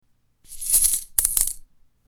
Coins Dropping 03
Coins_dropping_03.mp3